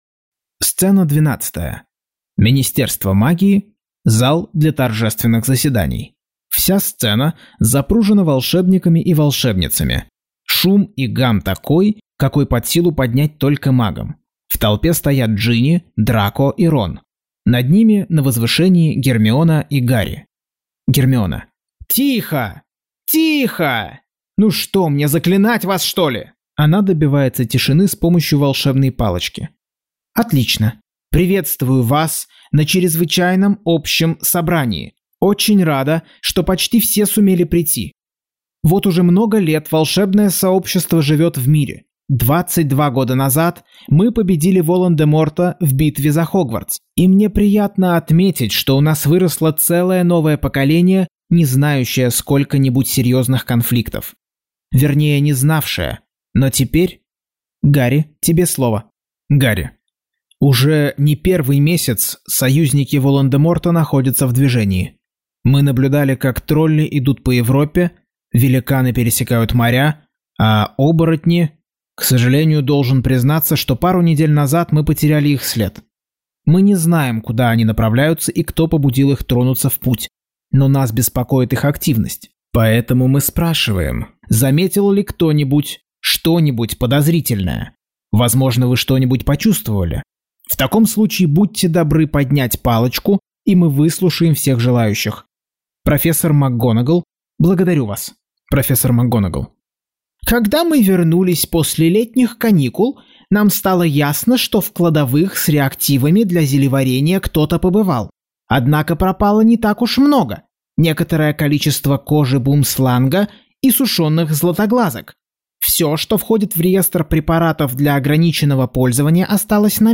Аудиокнига Гарри Поттер и проклятое дитя. Часть 9.